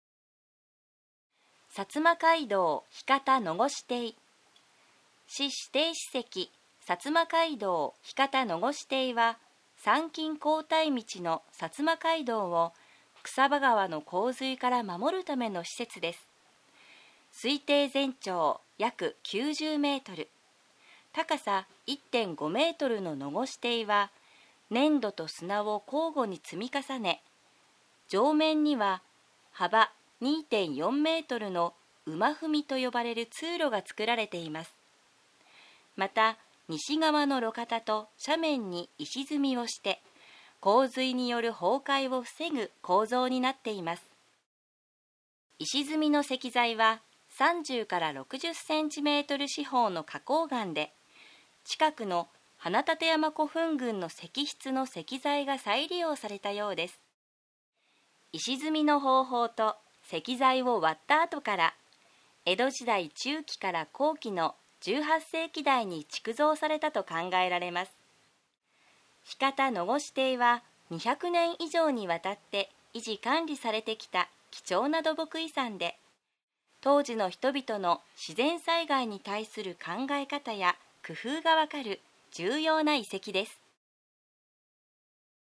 小郡市内文化財音声ガイダンス